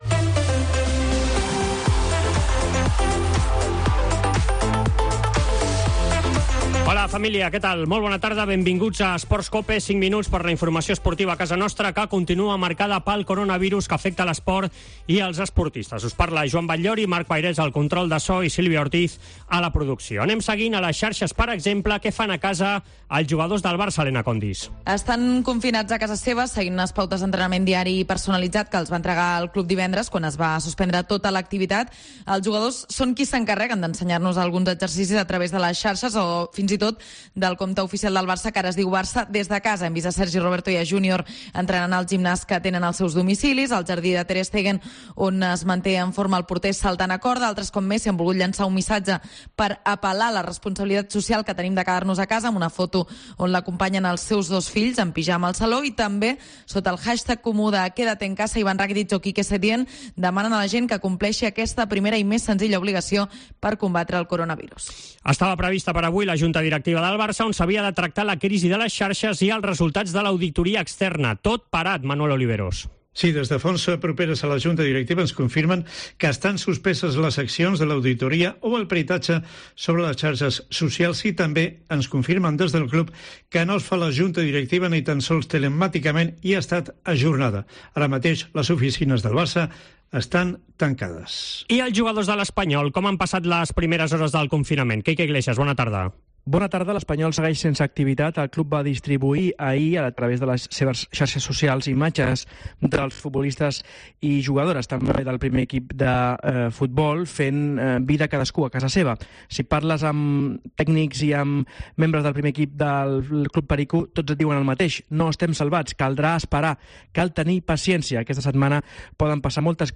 i tot l'equip treballant des de casa.